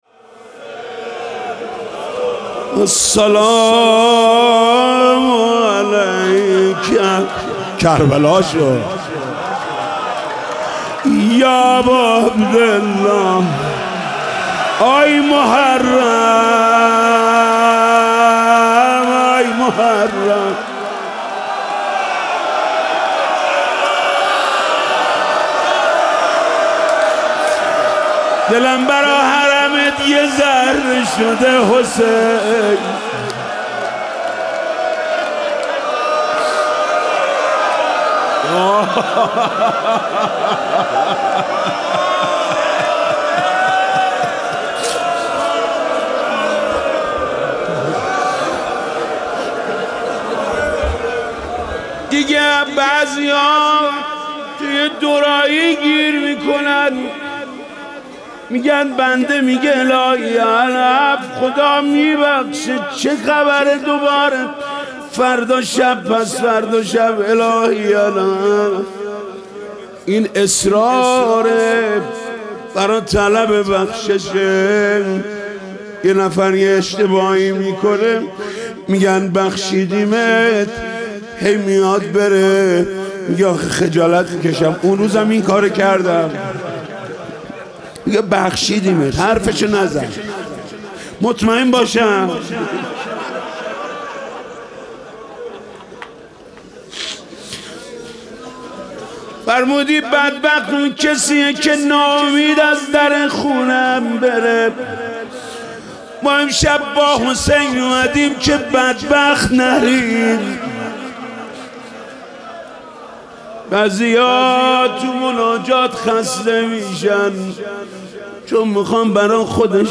مناجات
روضه
قرائت زیارت عاشورا